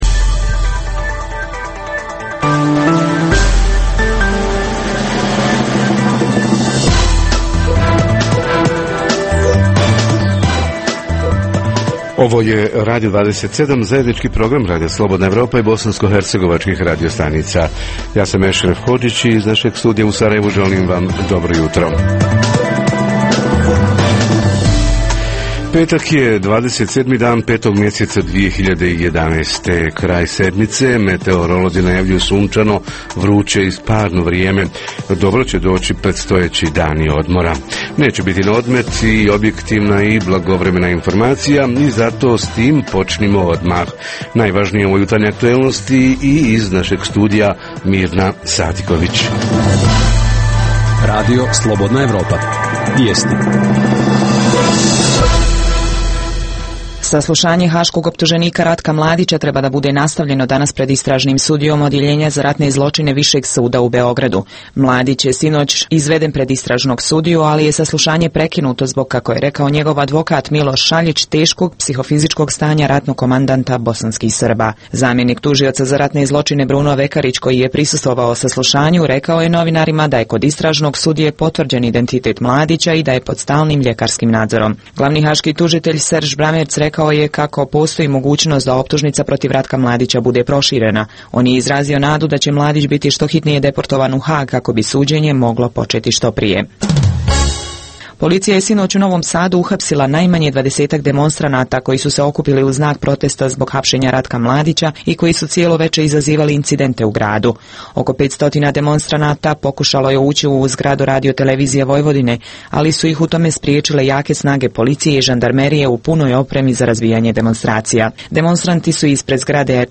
Tema jutra: dugovanja građana za komunalne usluge, tužbe komunalnih preduzeća i zatrpanost sudova komunalnim predmetima – da li je prihvatljivo i koliko se koristi alternativno rješavanje komunalnih sporova? Reporteri iz cijele BiH javljaju o najaktuelnijim događajima u njihovim sredinama.
Redovni sadržaji jutarnjeg programa za BiH su i vijesti i muzika.